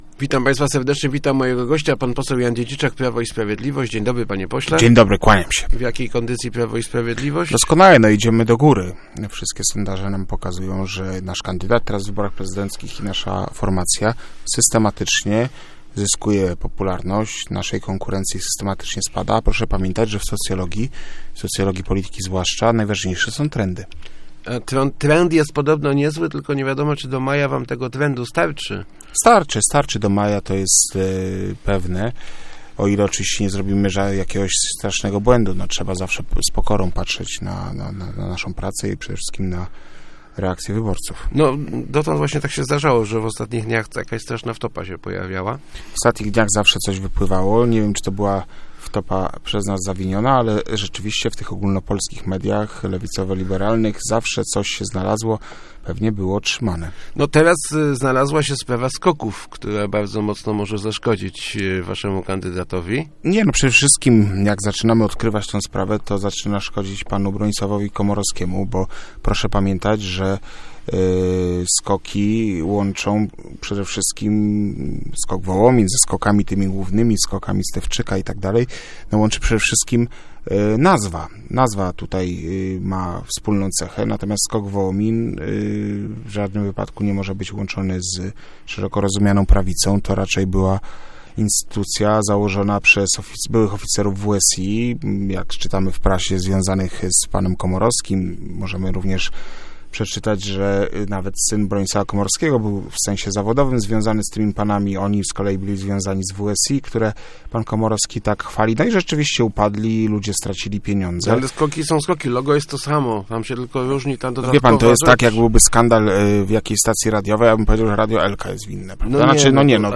Sprawa SKOKów to temat zastępczy, w tych wyborach chodzi o wiele poważniejsze rzeczy, na przykład przyszłość złotówki - mówił w Rozmowach Elki poseł Jan Dziedziczak z PiS.